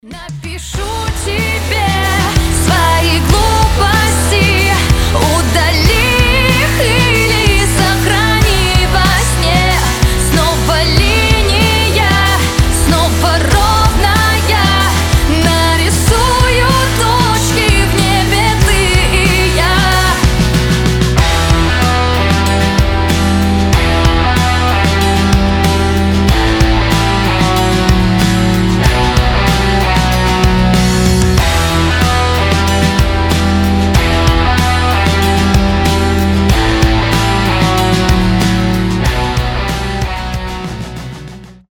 • Качество: 320, Stereo
громкие
женский голос
Pop Rock